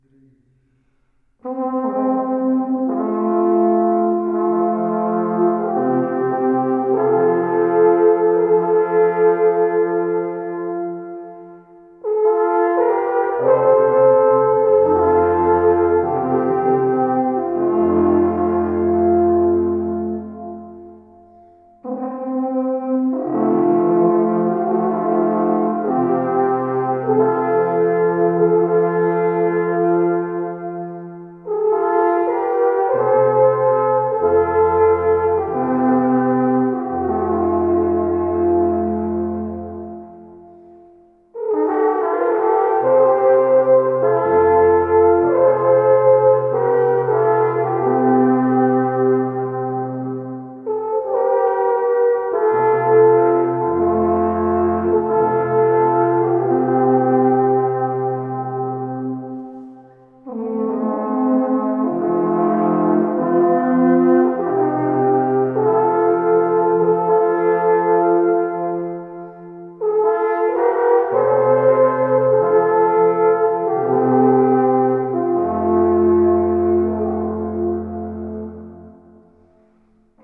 De prachtige klank die hier in de kathedraal van Bel werd al wel eens eerder bezongen.
Van de repetitie werd een opname gemaakt, waarvan we hier een voorbeeldje te geven.